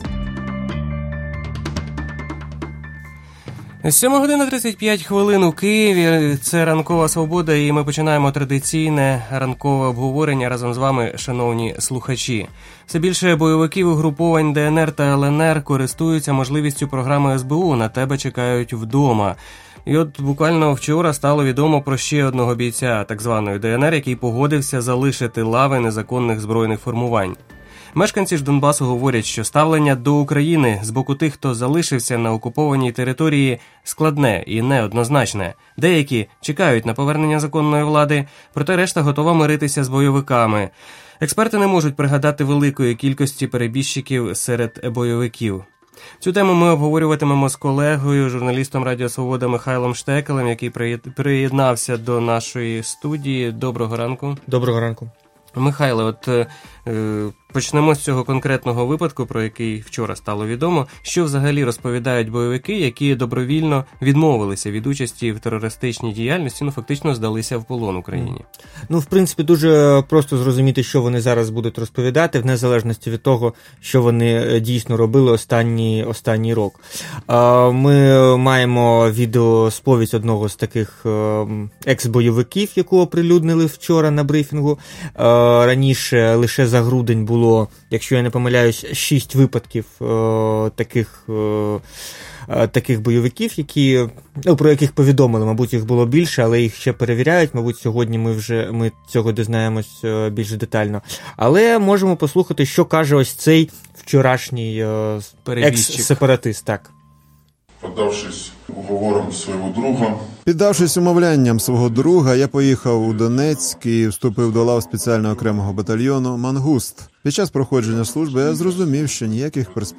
Цю тему обговорювали в ефірі «Ранкової Свободи».